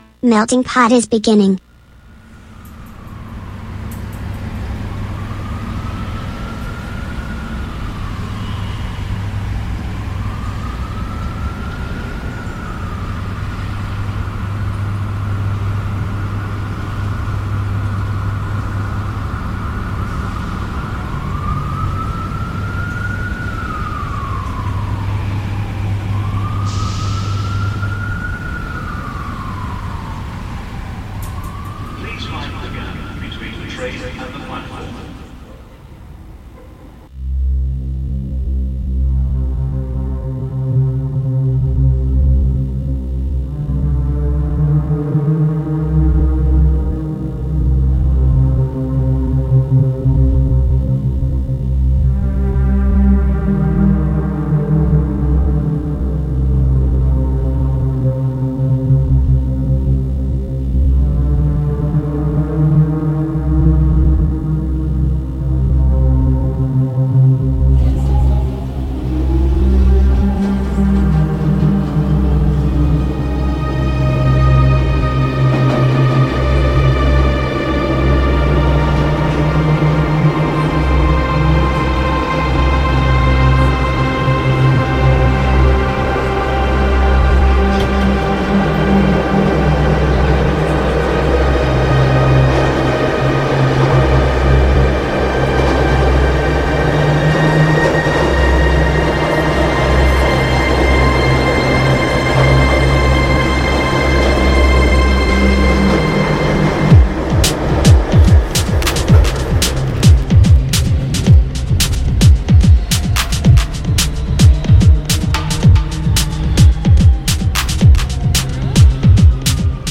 MUSICA NOTIZIE INTERVISTE A MELTINGPOT | Radio Città Aperta
Musica, notizie, curiosità e attualità a 360°: MeltingPot è il podcast settimanale di Radio Città Aperta che mescola suoni e storie, unendo ritmi diversi e voci da ogni parte del mondo.